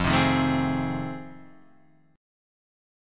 F7#9 chord